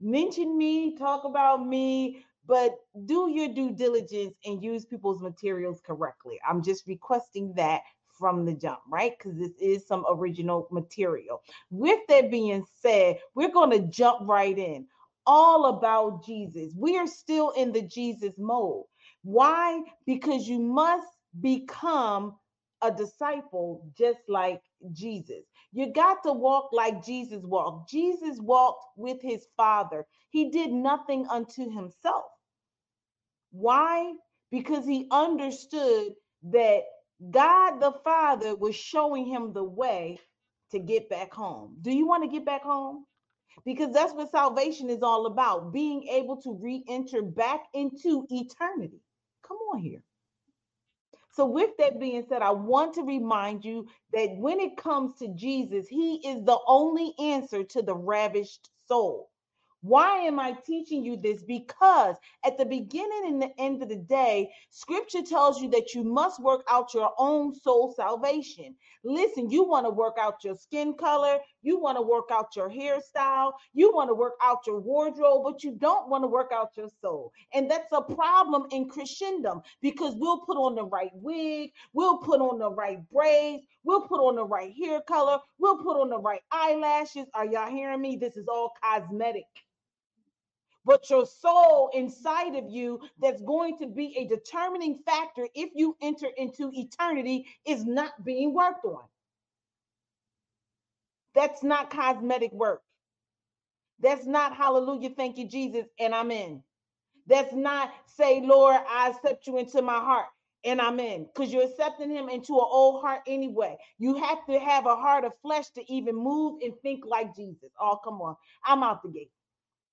I AM THE DESTROYER PART II Reign Embassy Sunday Morning Services podcast